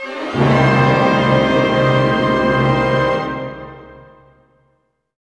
Index of /90_sSampleCDs/Roland - String Master Series/ORC_Orch Gliss/ORC_Minor Gliss